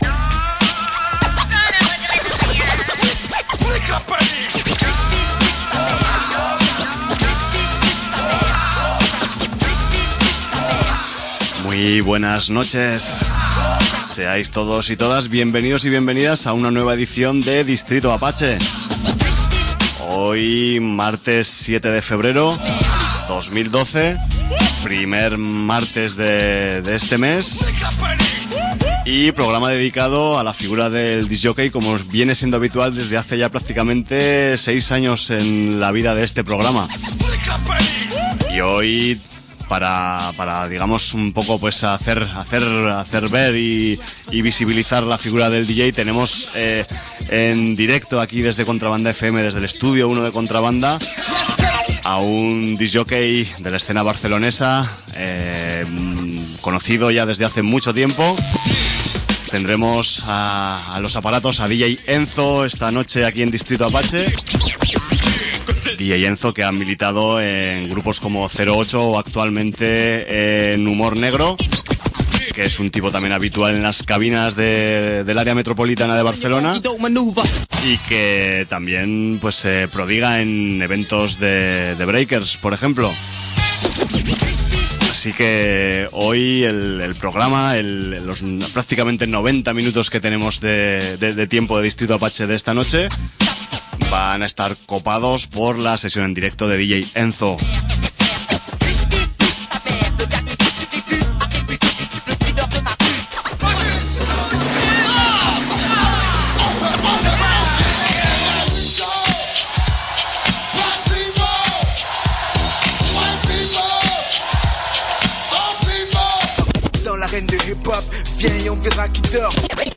en el estudio